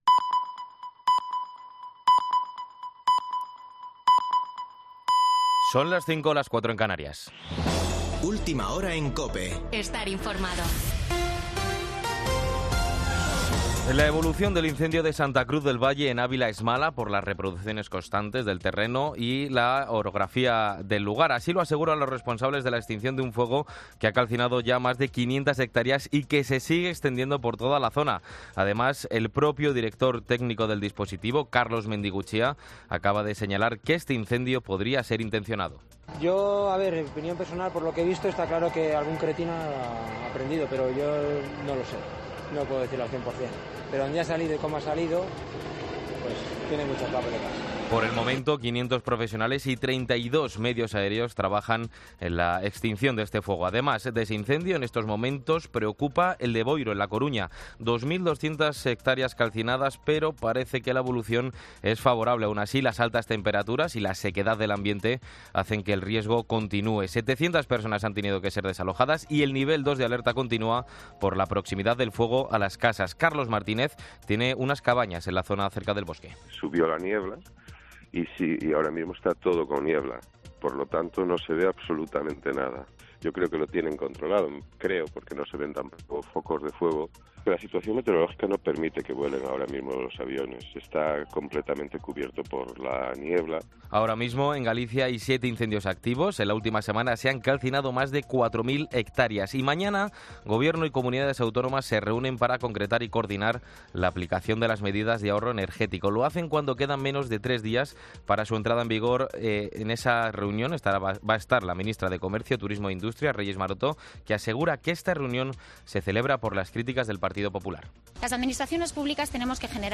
Boletín de noticias de COPE del 7 de agosto de 2022 a las 17.00 horas